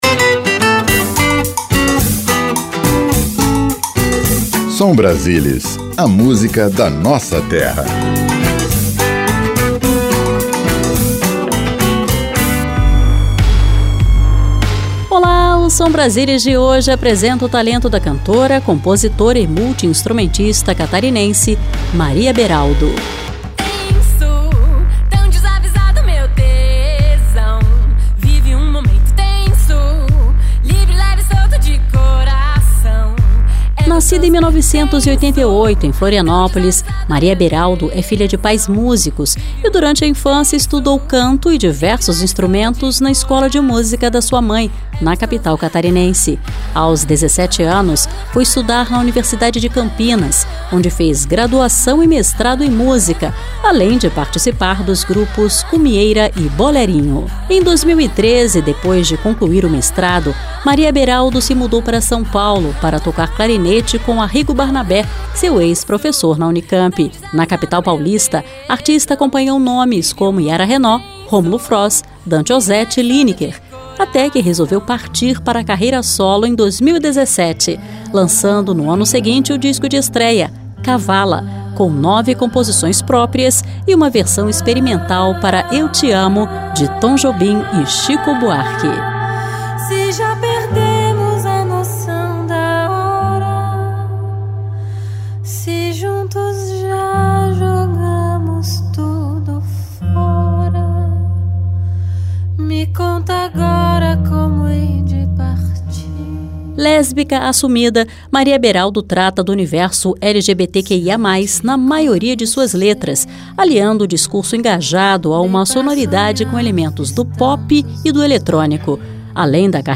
Seleção Musical: